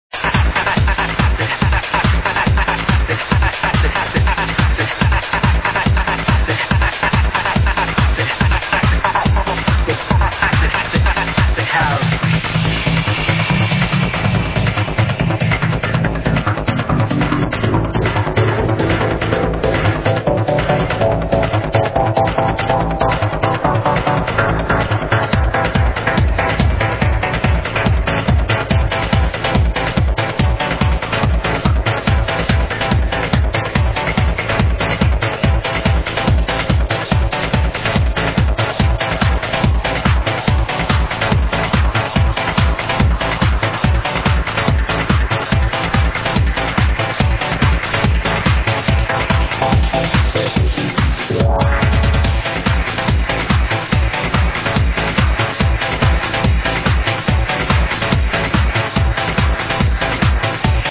its kinda funkyish... pls ID if u can.